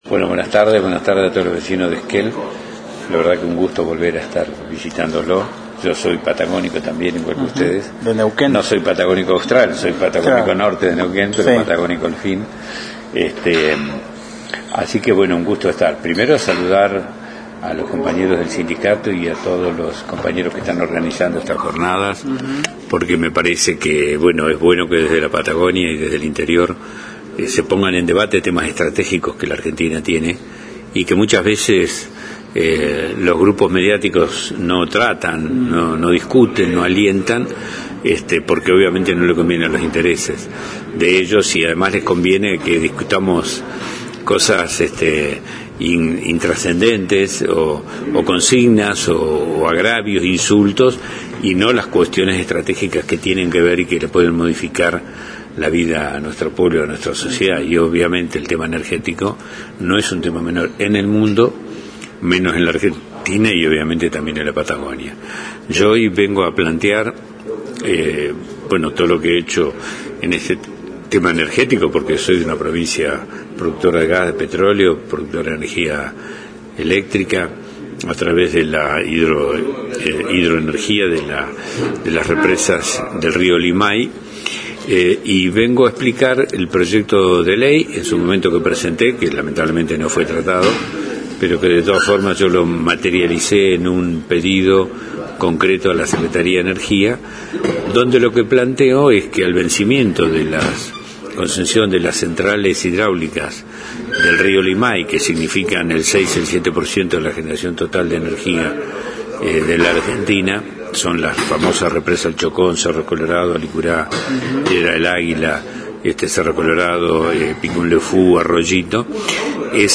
En diálogo con el móvil de Radio Nacional Esquel, habló del proyecto sobre la continuidad de las concesiones hidroeléctricas de jurisdicción nacional.